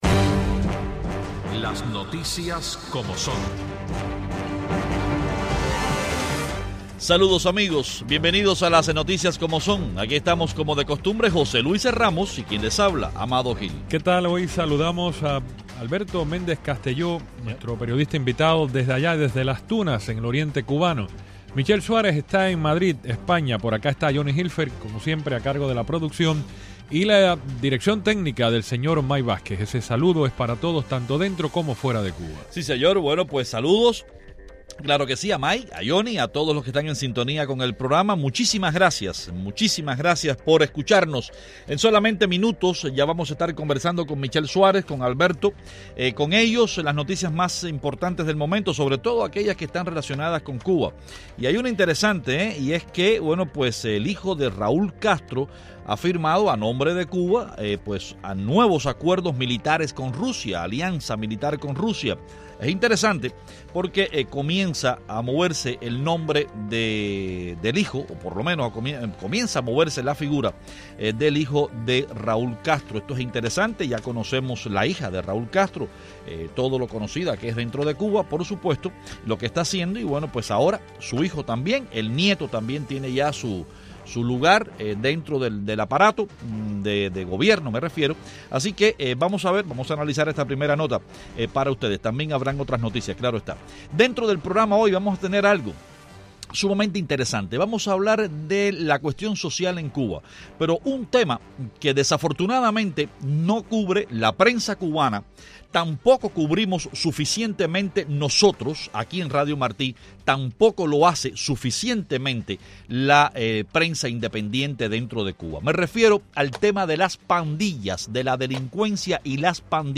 Los periodistas cubanos